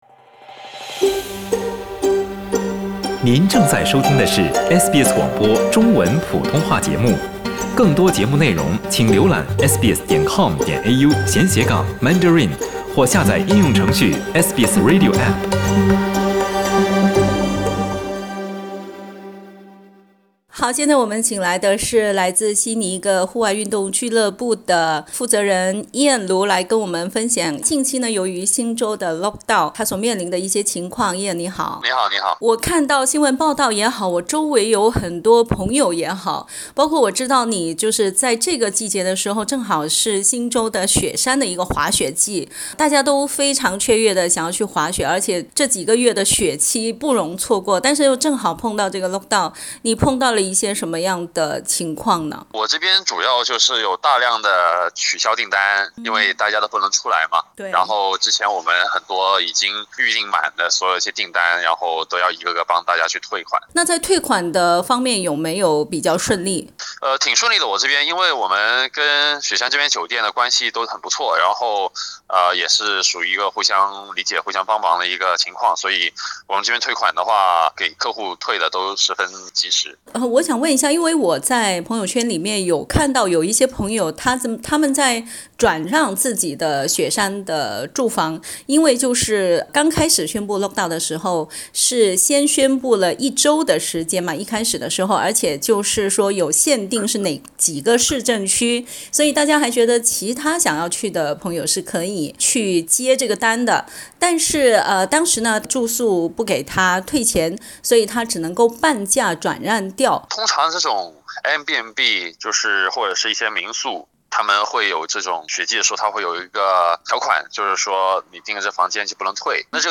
（请听采访） 澳大利亚人必须与他人保持至少1.5米的社交距离，请查看您所在州或领地的最新社交限制措施。